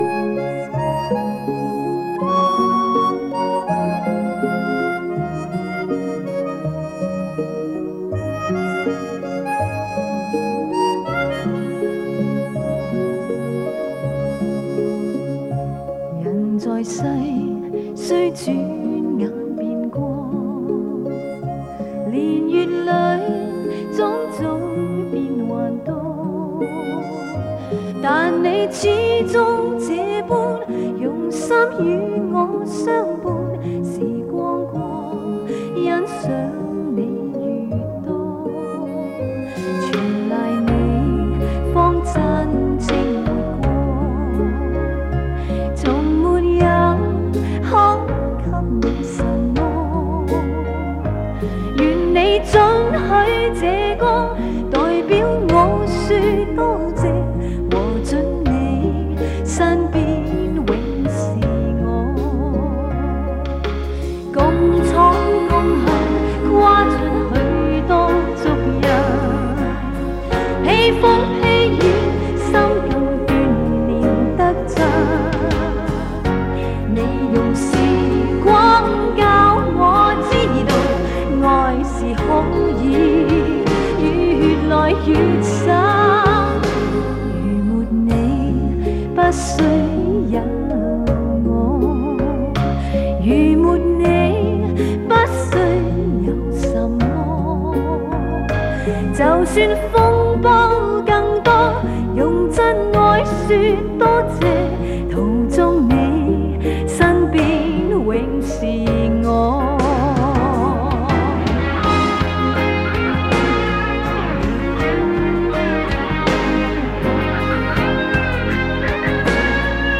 磁带数字化：2022-09-29